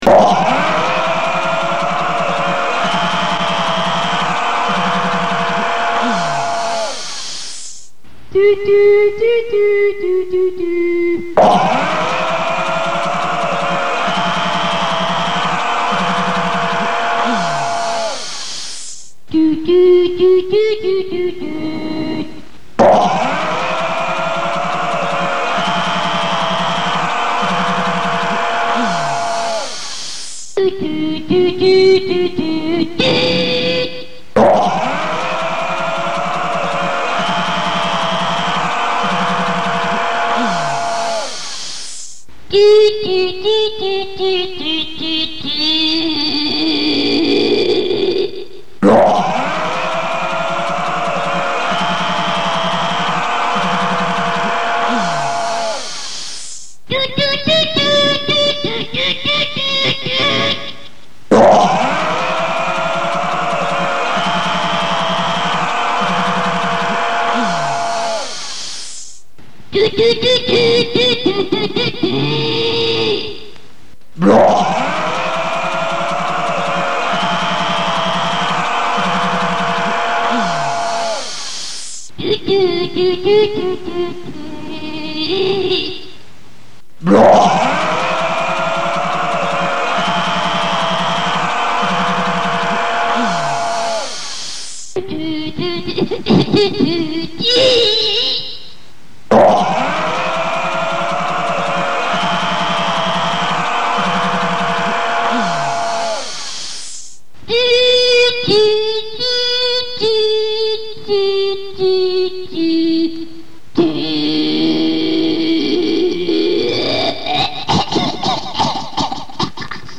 R.: ecco qua il nostro album sperimentale.